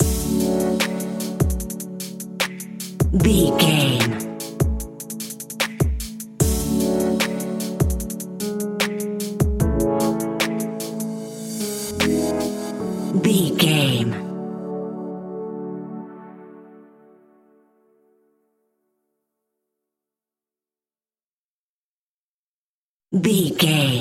Ionian/Major
hip hop
instrumentals
chilled
laid back
groove
hip hop drums
hip hop synths
piano
hip hop pads